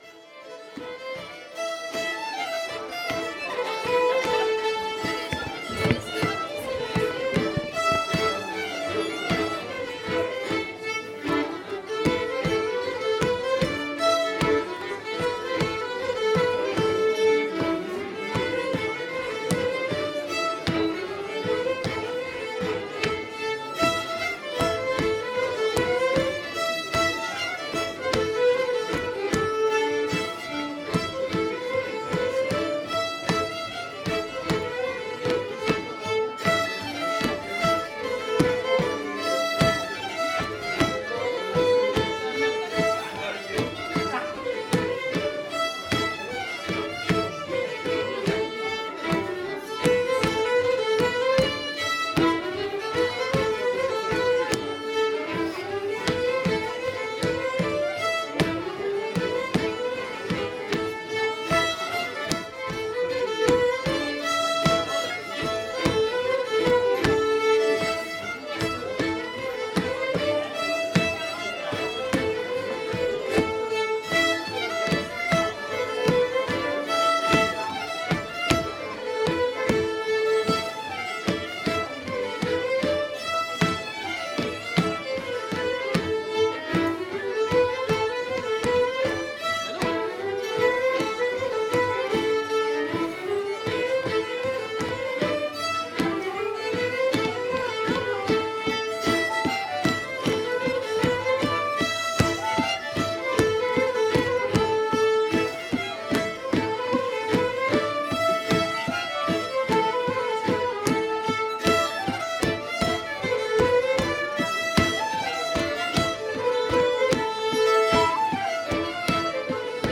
:mp3:2013:soiree_stagiaires
01_gavotte-violons.mp3